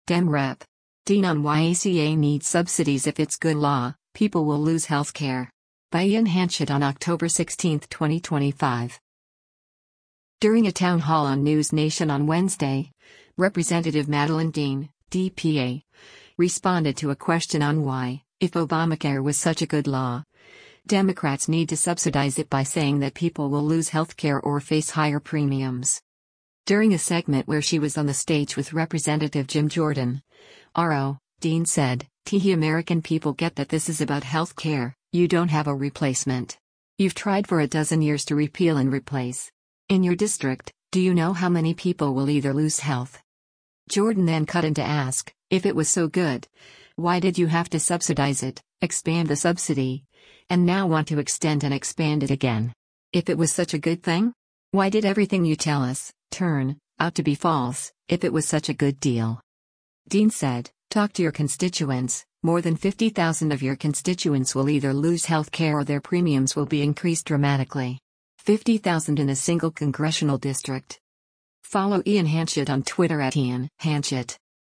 During a town hall on NewsNation on Wednesday, Rep. Madeleine Dean (D-PA) responded to a question on why, if Obamacare was such a good law, Democrats need to subsidize it by saying that people will lose health care or face higher premiums.
Jordan then cut in to ask, “If it was so good, why did you have to subsidize it, expand the subsidy, and now want to extend and expand it again? If it was such a good thing? Why did everything you tell us [turn] out to be false, if it was such a good deal?”